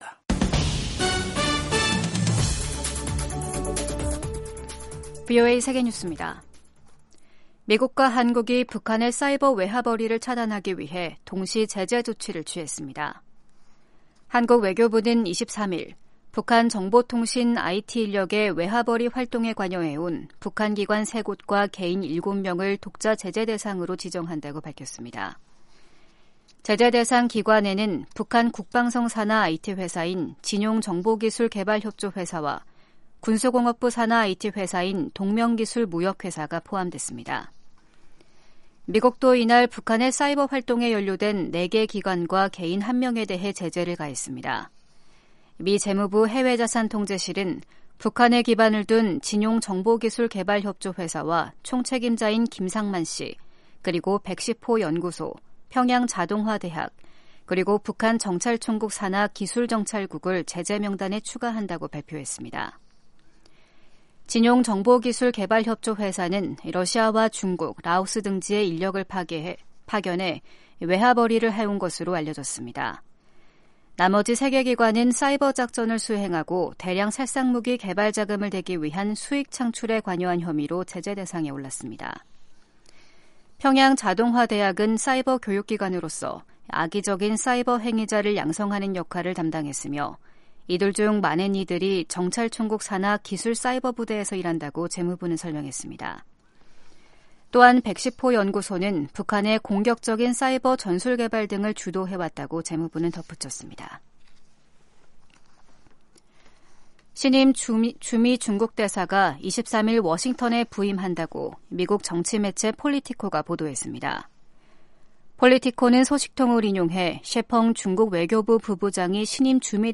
세계 뉴스와 함께 미국의 모든 것을 소개하는 '생방송 여기는 워싱턴입니다', 2023년 5월 24일 아침 방송입니다. '지구촌 오늘'에서는 볼로디미르 젤렌스키 우크라이나 대통령이 참가한 가운데 주요7개국(G7) 정상회의가 마무리 된 소식 전해드리고, '아메리카 나우'에서는 부채한도 협상을 위해 조 바이든 대통령과 케빈 매카시 하원의장이 다시 만나는 이야기 살펴보겠습니다.